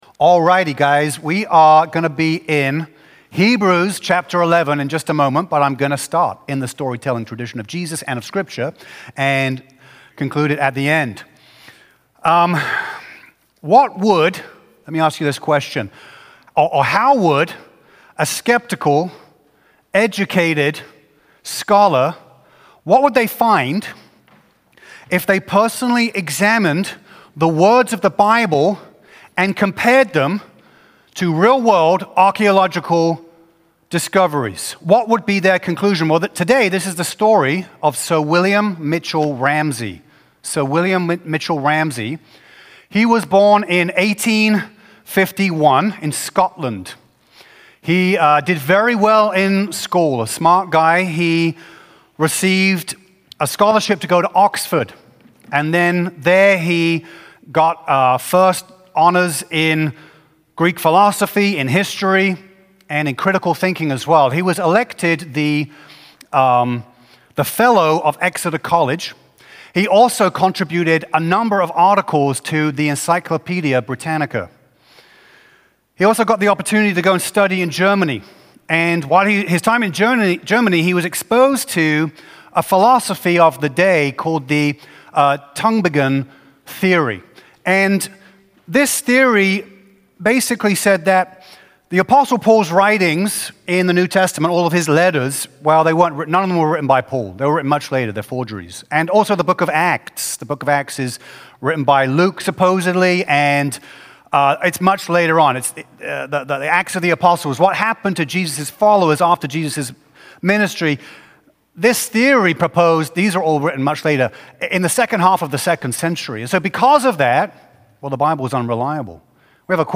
A message from the series "Bible Revival."